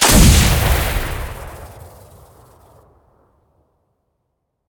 sniper1.ogg